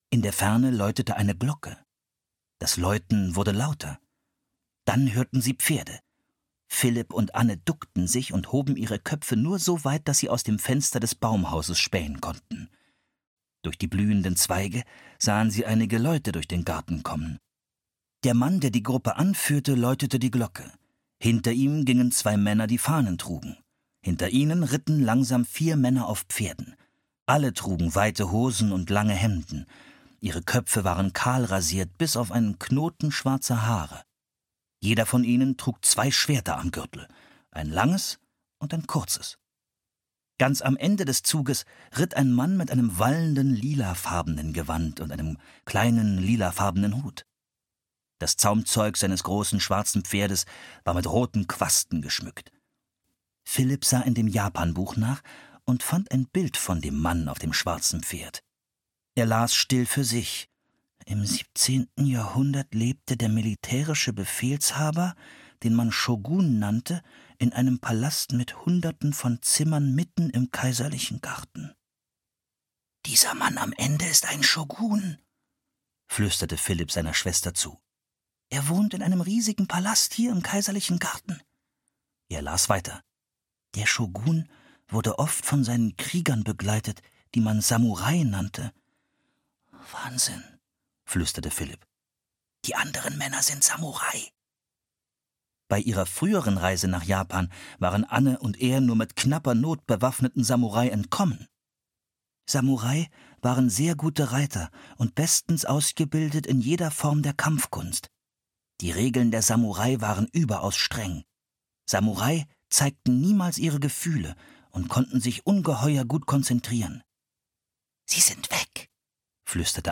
Angriff des Wolkendrachen (Das magische Baumhaus 35) - Mary Pope Osborne - Hörbuch